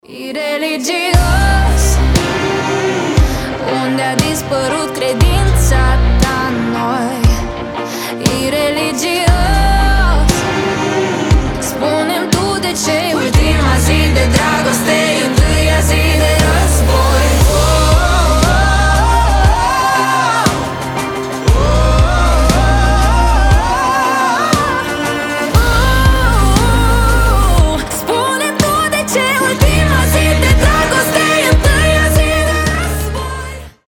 • Качество: 320, Stereo
румынские
сильный голос